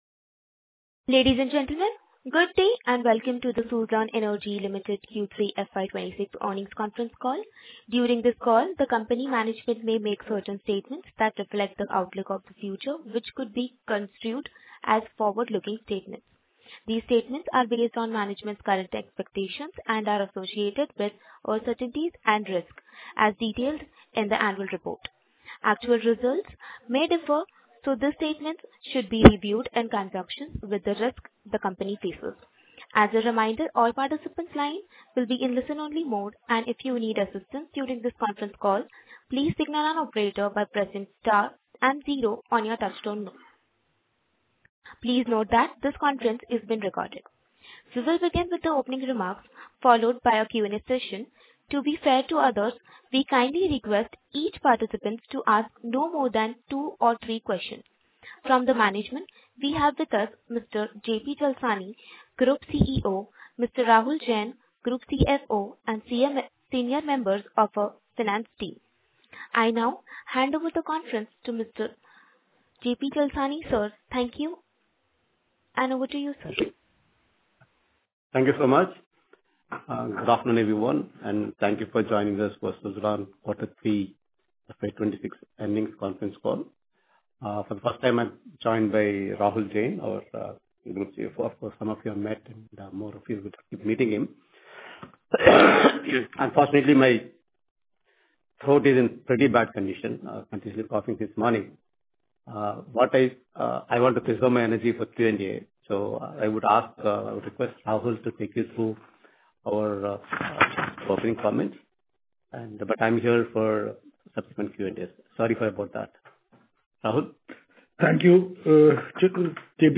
Audio Recording - Conference Call